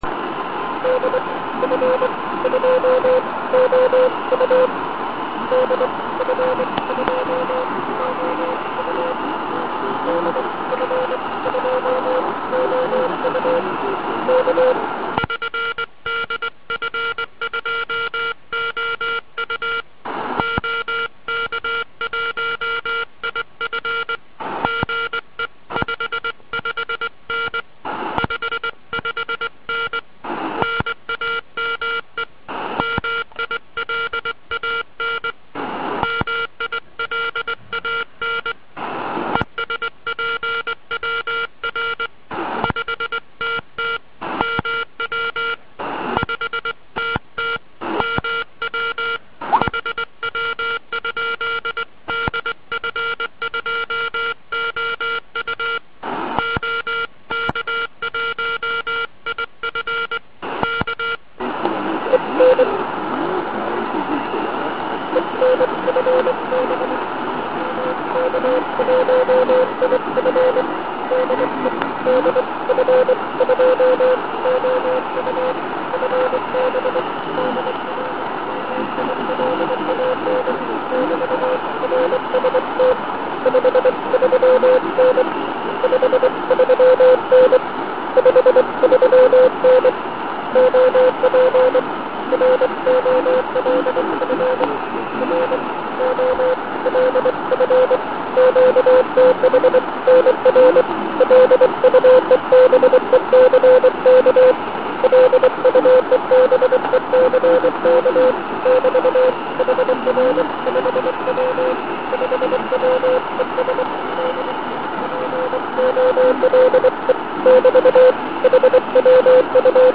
Přeci jenom je však vidět, že "loviči" jsou vycvičení v příjmu slabých signálků.
Teprve po následné digitální úpravě signálů to bylo docela dobře čitelné.